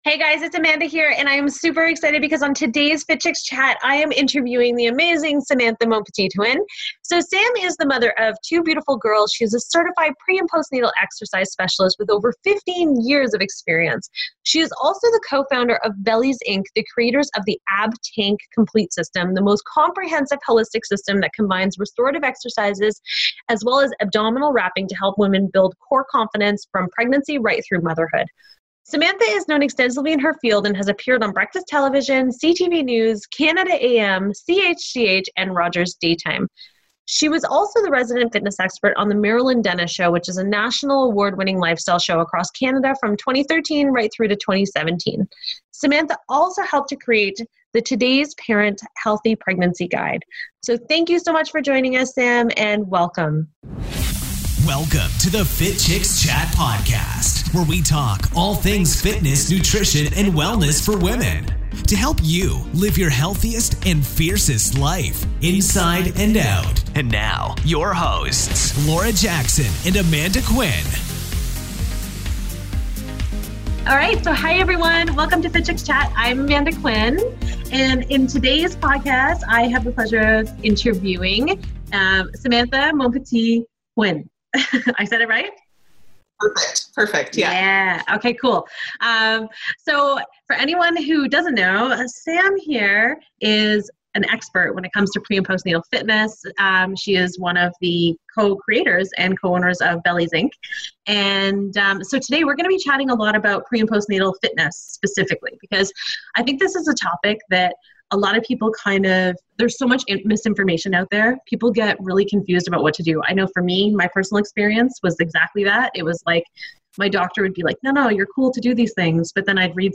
Episode #72: INTERVIEW